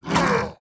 Index of /App/sound/monster/orc_general
attack_1.wav